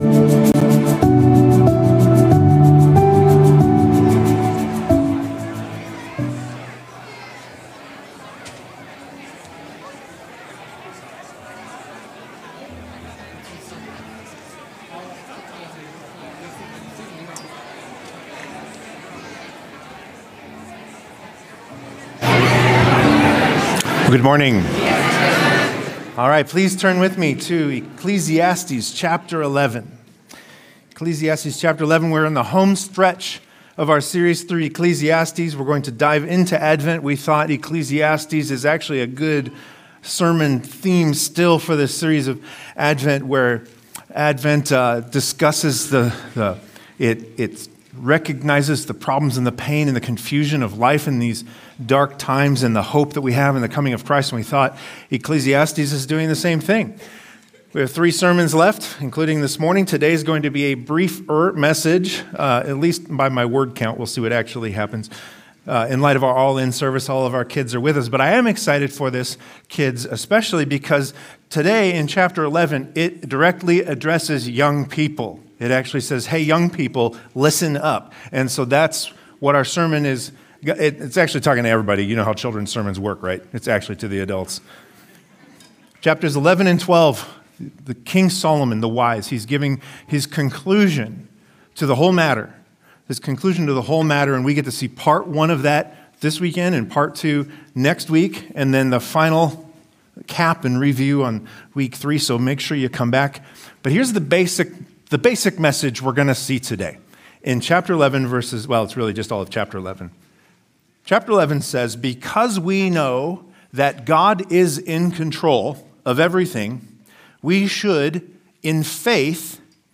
Three sermons left.